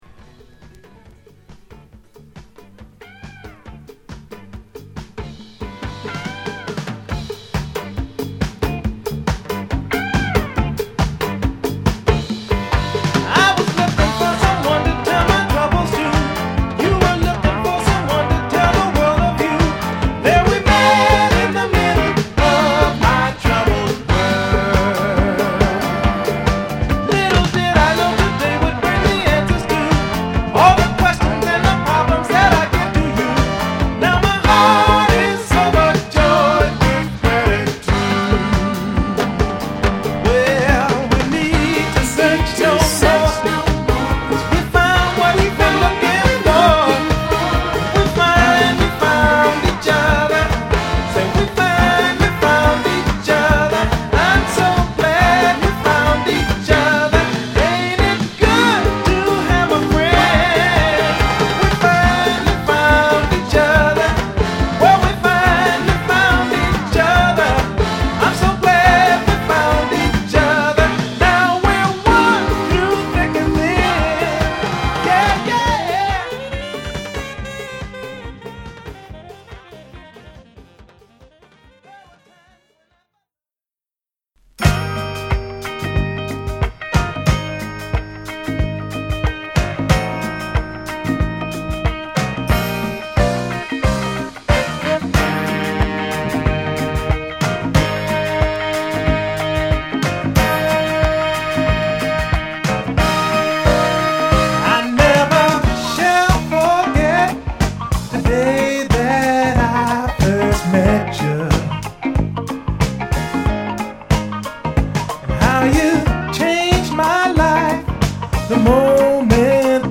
爽やかなディスコチューンB2
試聴B2→B4